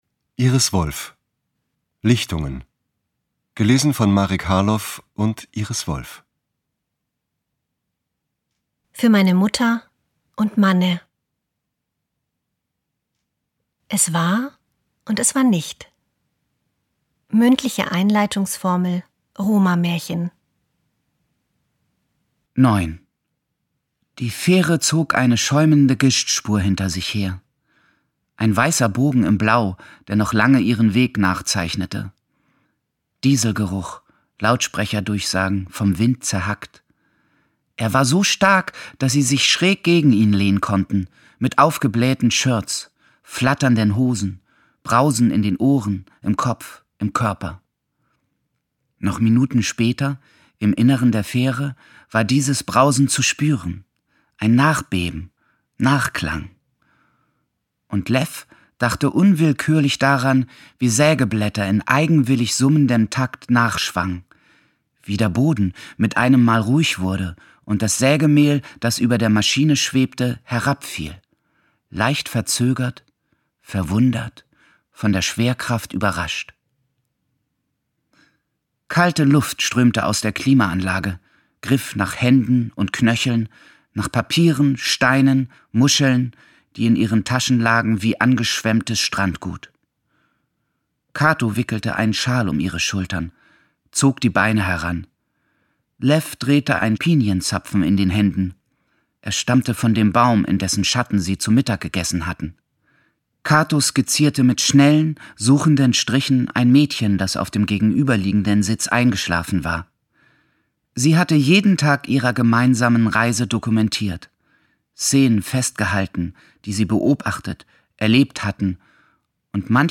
Schlagworte Bestseller • Buch zum Lachen und Weinen • CD • Die Unschärfe der Welt • Erste Liebe • Europa • Familiengeschichte • Freundschaft • Hörbuch • Kindheitserinnerungen • Liebe • Lieblingsbuch • Monika Helfer • neue deutsche Heimatliteratur • neuerscheinung 2024 • Rumänien • Shortlist • Siebenbürgen • Ungekürzte Lesung • Zsuzsa Bánk